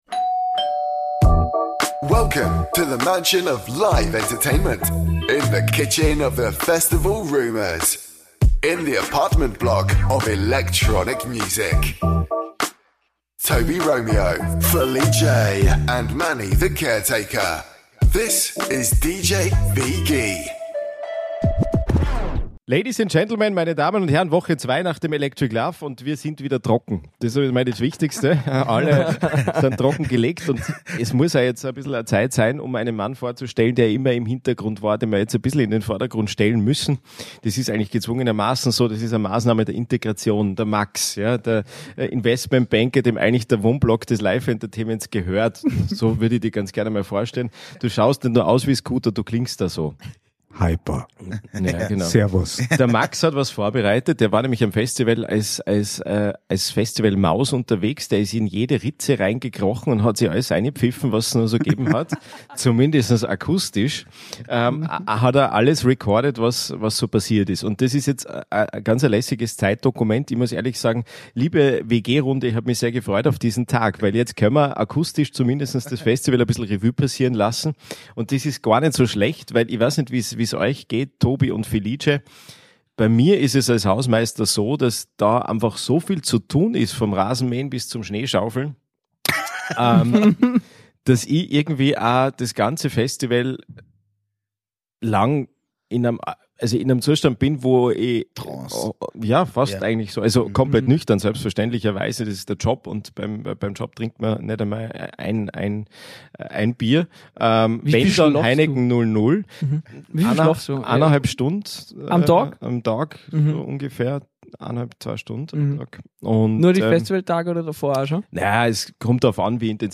In der DJ WG wird dies dann vorgespielt, kommentiert, analysiert und "zerlegt".